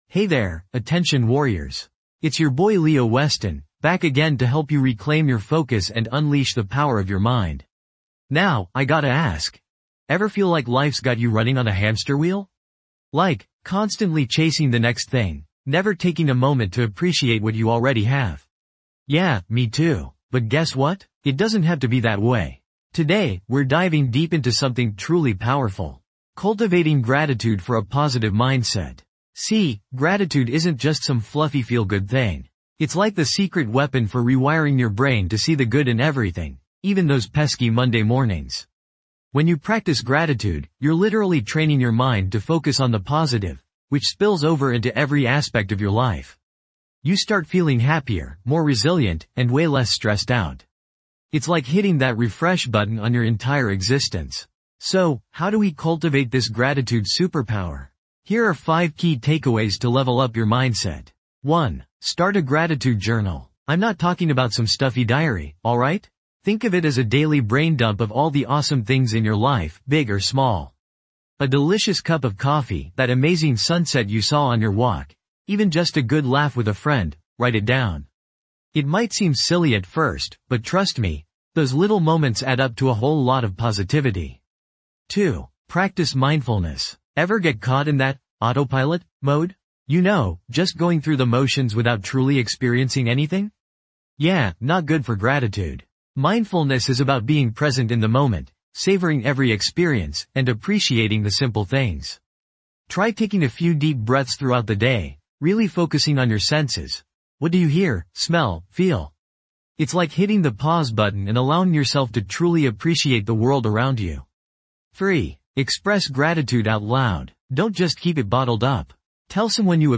Key Takeaways:. - Understand the science behind gratitude and its benefits for mental health - Explore simple exercises to integrate gratitude into your daily routine - Experience a guided gratitude meditation for immediate positive effects
This podcast is created with the help of advanced AI to deliver thoughtful affirmations and positive messages just for you.